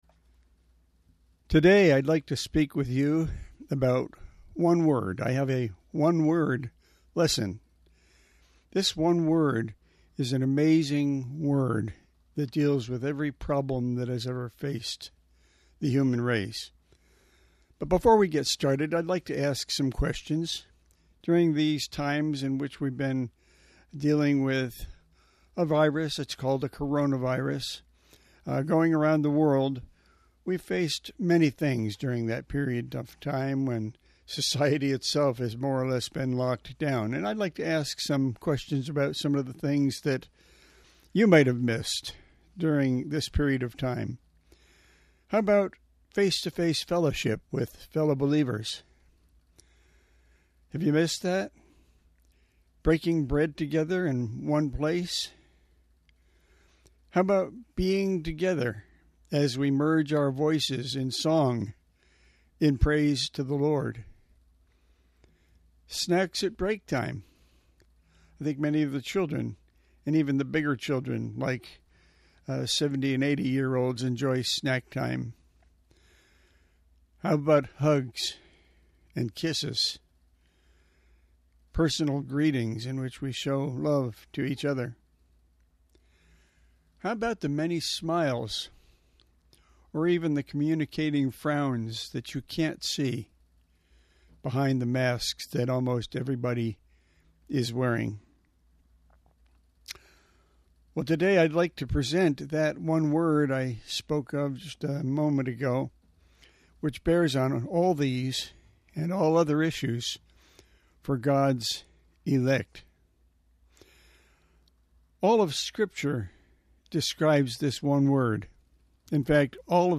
Handouts showing Scriptures discussed in sermon audio